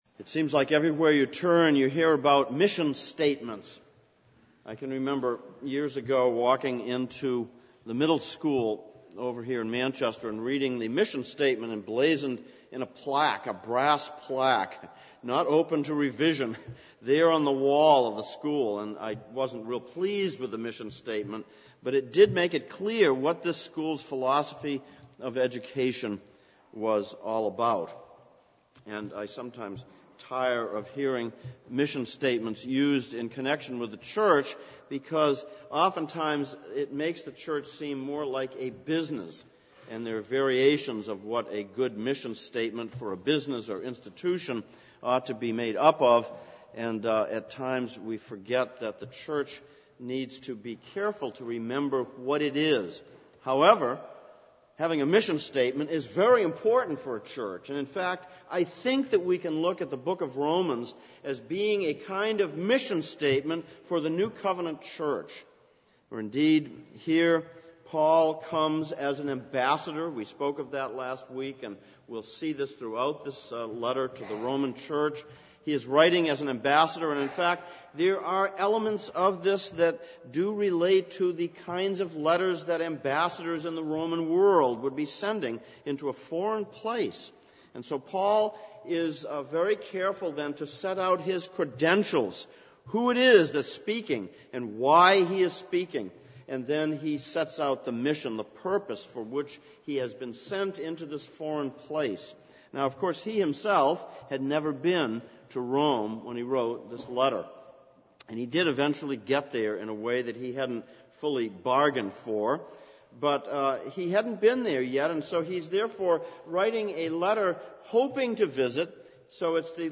Exposition of Romans Passage: Romans 1:1-7 Service Type: Sunday Morning « 04.